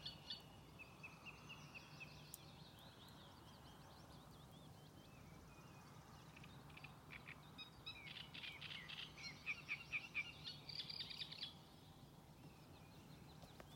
Great Reed Warbler, Acrocephalus arundinaceus
StatusSinging male in breeding season